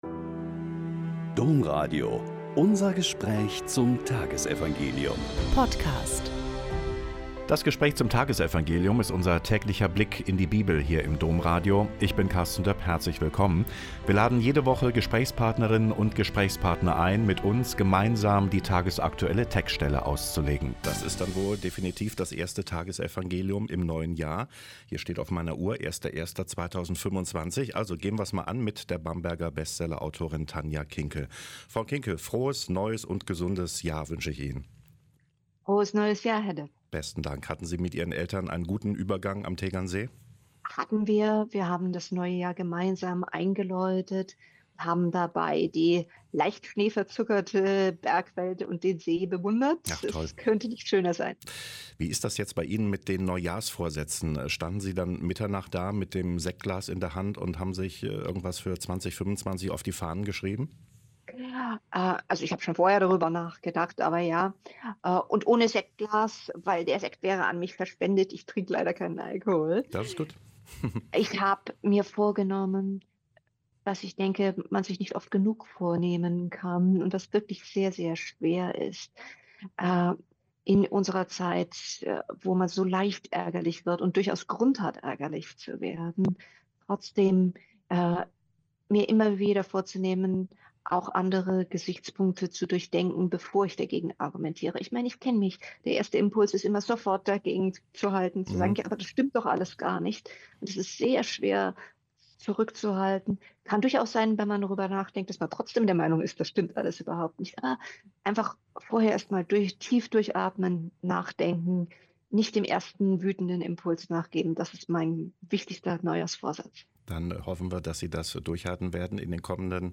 Lk 2,16-21 - Gespräch mit Dr. Tanja Kinkel